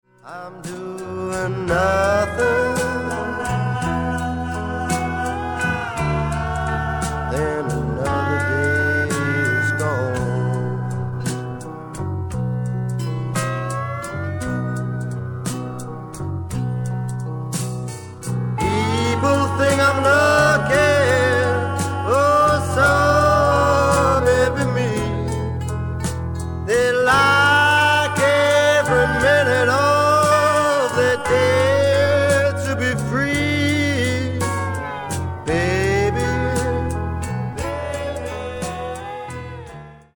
BLUES ROCK / COUNTRY BLUES / SSW